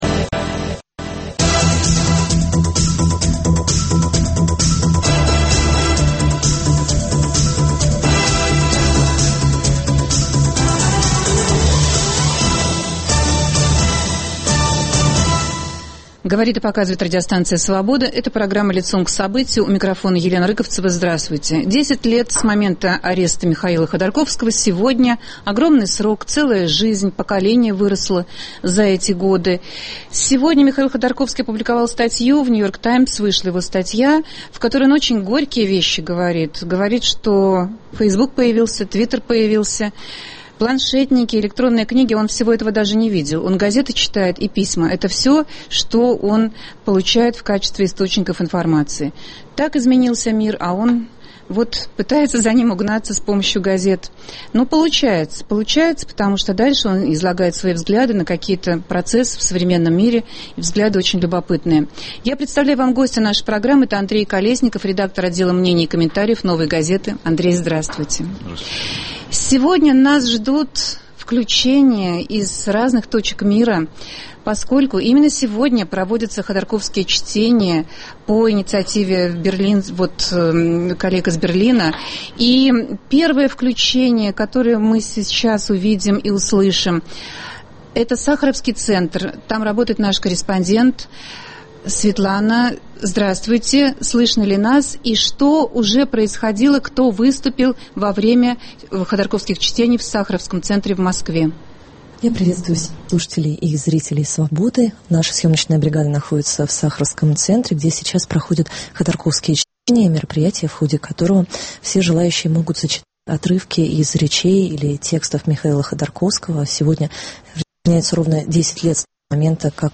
Каким вы видите будущее Михаила Ходорковского? Гость студии